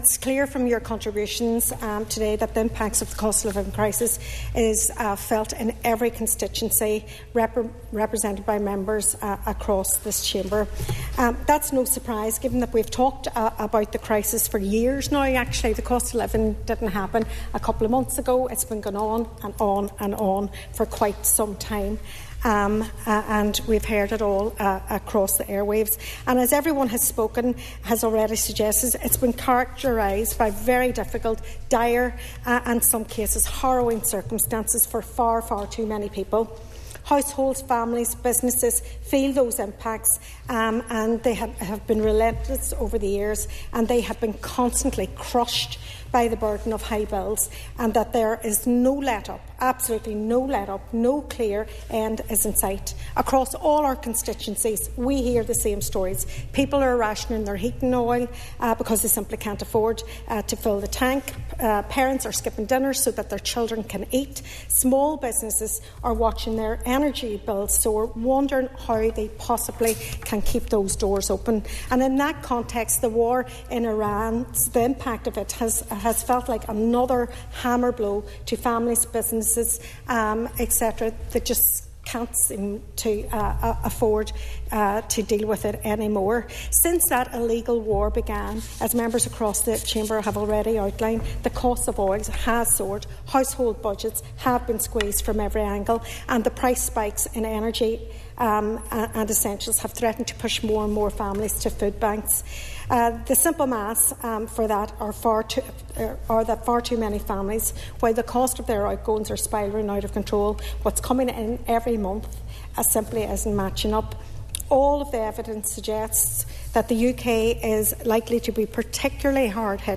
A debate in the Stormont Assembly on the response to the cost of living crisis has heard calls to end the politics of blame.
During the debate, Foyle MLA Sinead McLaughlin said that the response of the Executive should be more than ‘blame it on the Brits’: